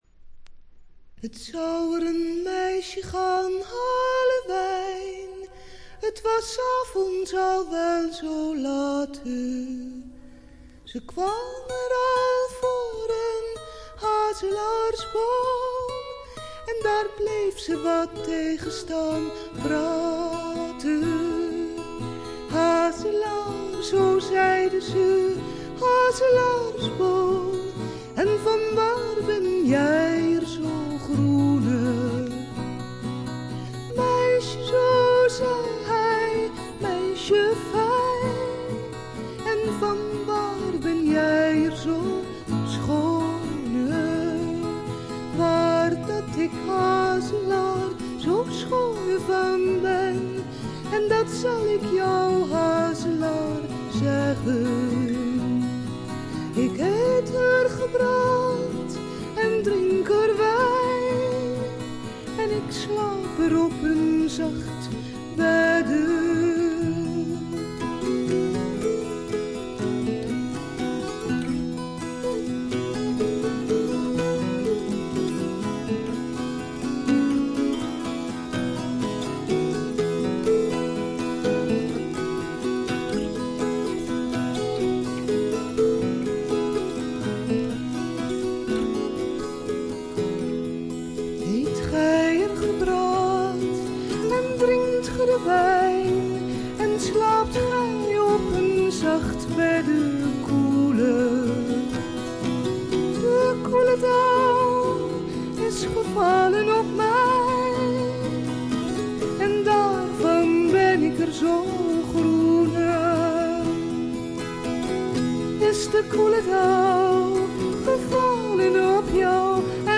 オランダのトラッド・グループ
アコースティック楽器のみのアンサンブルで美しいトラディショナル・フォークを聴かせます。
試聴曲は現品からの取り込み音源です。